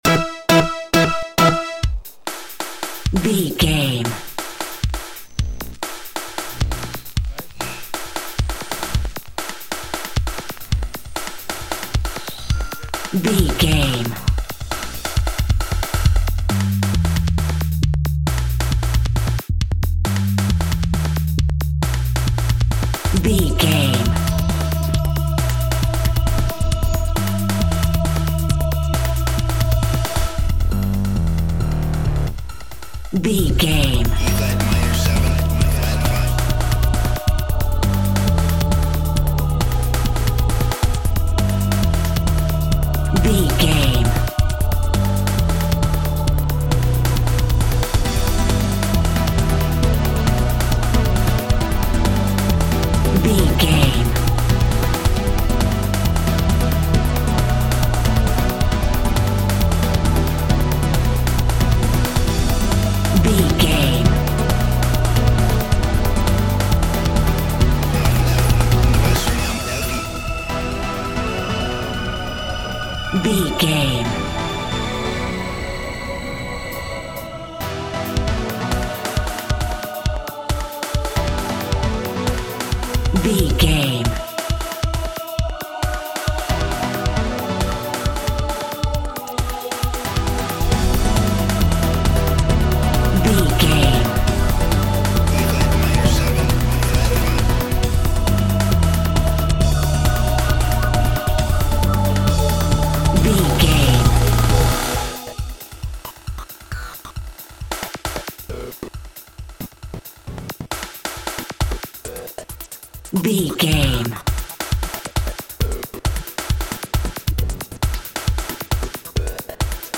Acid Style House Music Theme.
Epic / Action
Fast paced
Aeolian/Minor
Fast
dark
futuristic
intense
driving
energetic
synthesiser
vocals
drum machine
bass guitar
electric guitar
electro house
acid house music